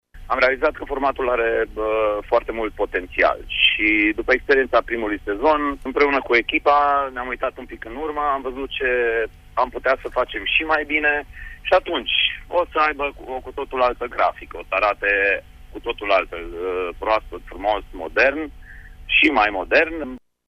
În sezonul al doilea, care va începe pe 2 octombrie, emisiunea va avea o înfățișare nouă a declarat prezentatorul emisiunii, Virgil Ianțu: